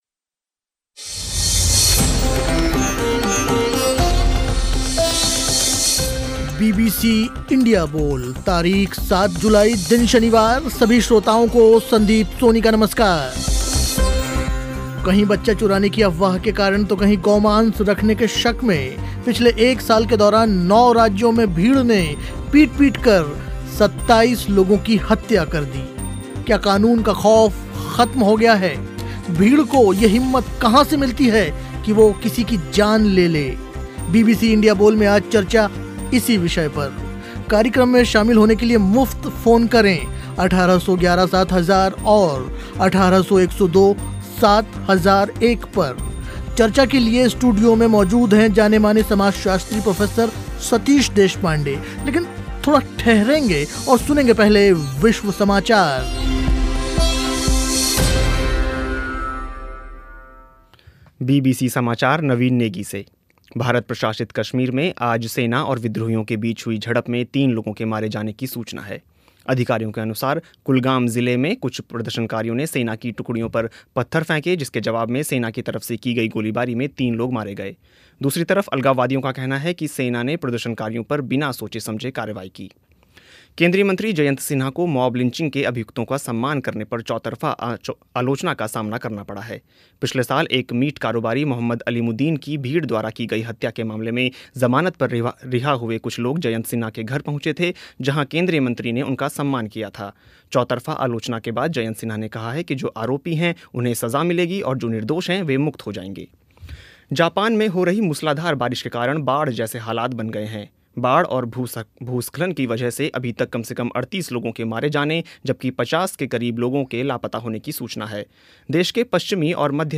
चर्चा के लिए स्टूडियो में मौजूद थे जानेमाने समाजशास्त्री प्रोफेसर सतीश देशपांडे.